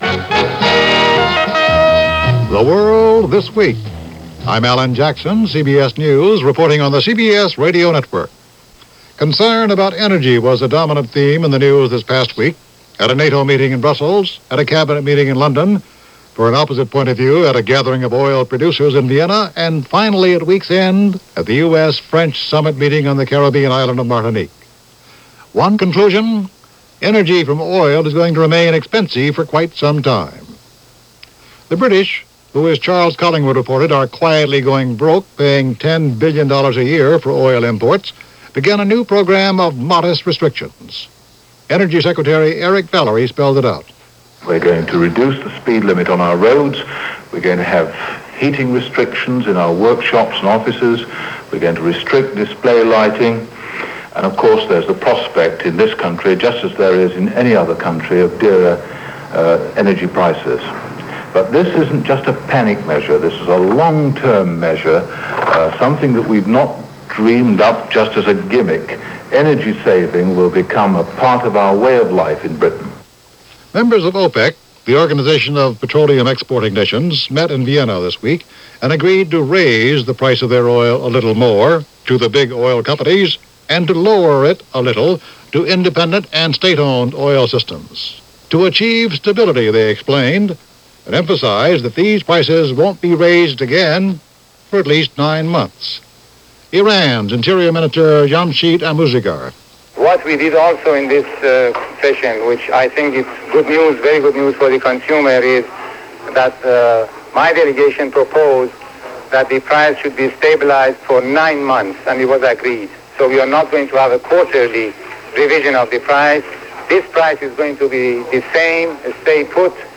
December 15, 1974 – The World This Week – CBS Radio News – Gordon Skene Sound Collection –
And that’s only a small slice of news for this December 15, 1974 as reported by The World This Week from CBS Radio.